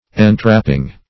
entrapping - definition of entrapping - synonyms, pronunciation, spelling from Free Dictionary